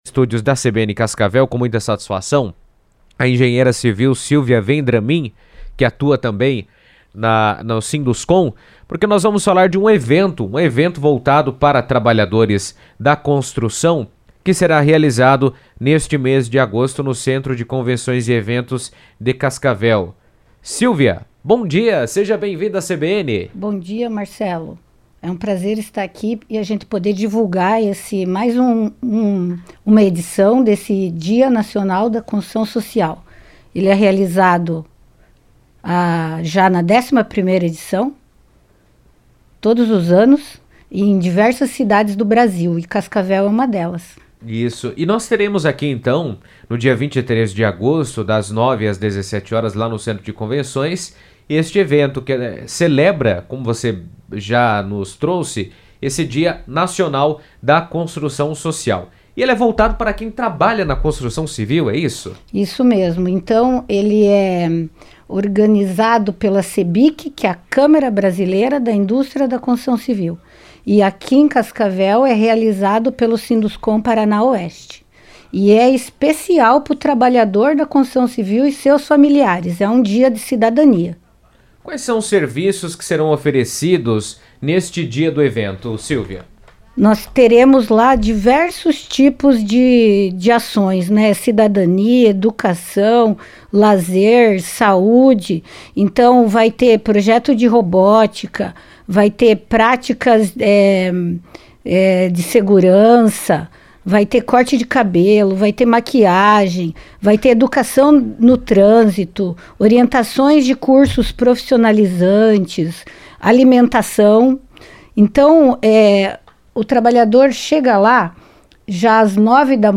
esteve na CBN falando sobre a programação e a importância do evento.